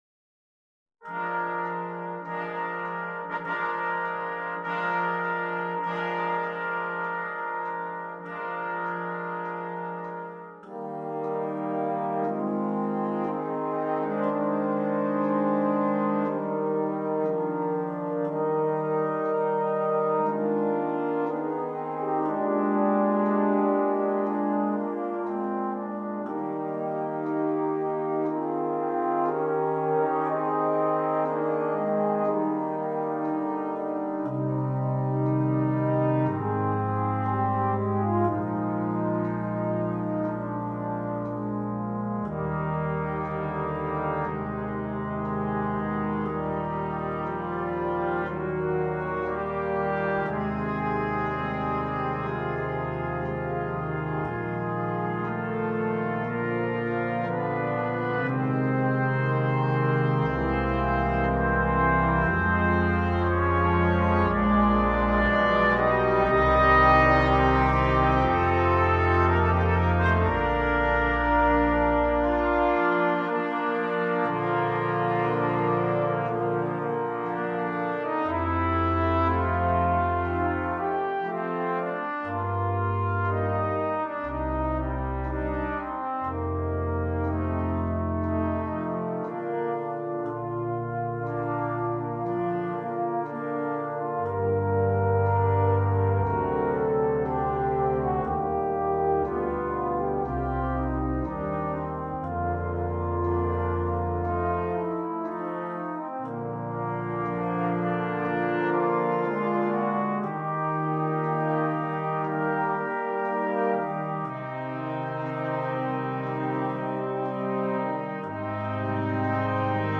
2. Brass Ensemble
10 brass players
without solo instrument
Classical
Part 4: Flugelhorn
Part 5: F Horn
Part 10: Tuba – Bass clef